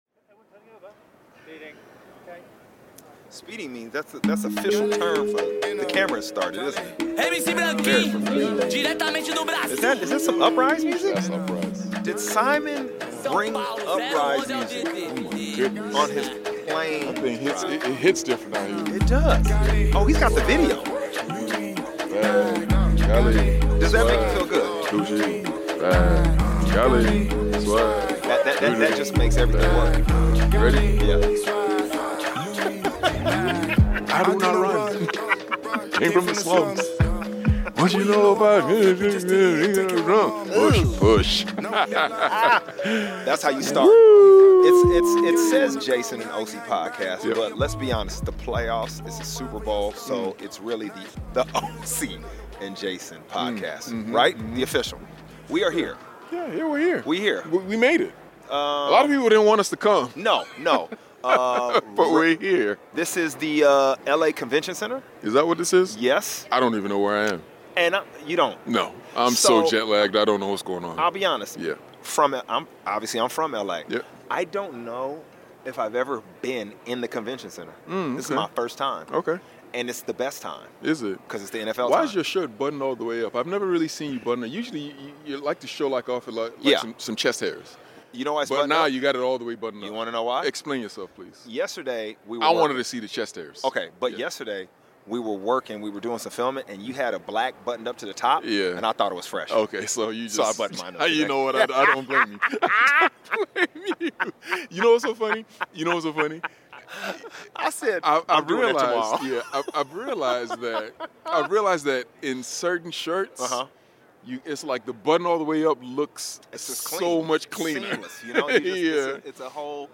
The first of two podcasts in two days as Jason & Osi heroically shake off jetlag and hit the NFL Media Centre's most colourful set to record the Osi and Jason Podcast. Brandon Marshall, former New York Jets, Miami Dolphins, Chicago Bears and 6 time Pro Bowler busts in and Osi pays respect to the I am Athlete show. And the boys respond to being in Los Angeles in different ways!